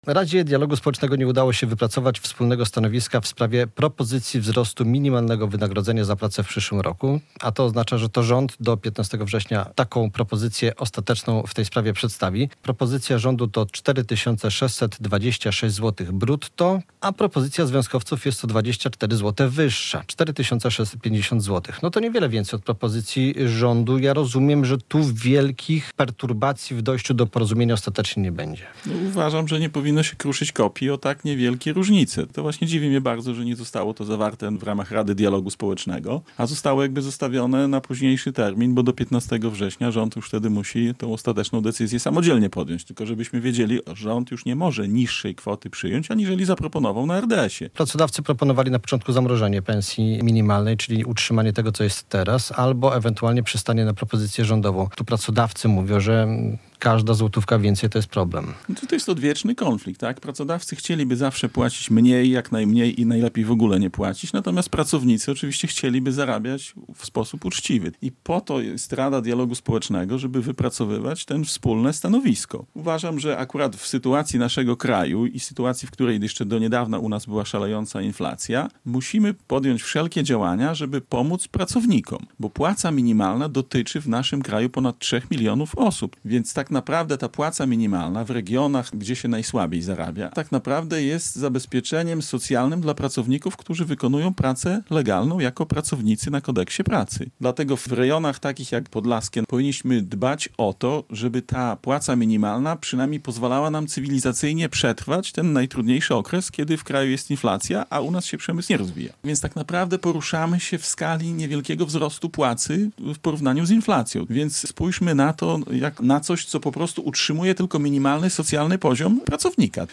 Gość